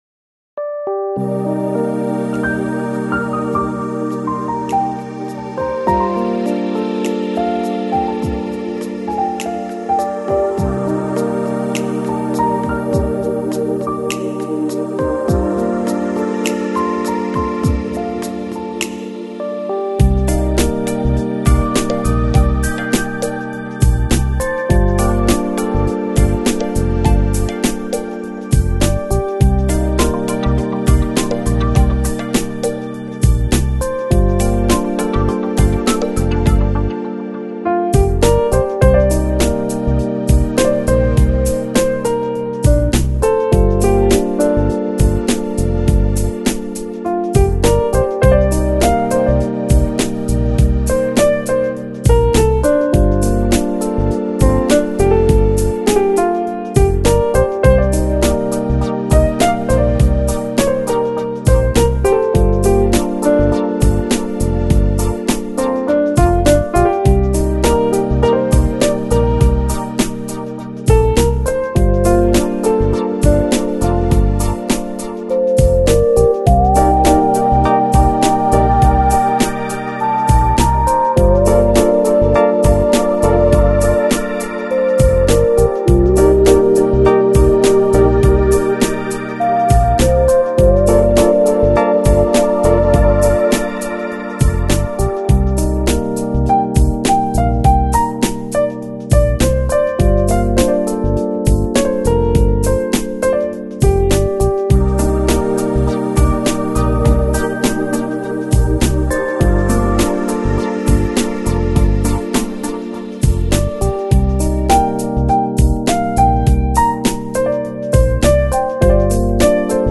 Smooth Jazz, Lounge, Chillout Издание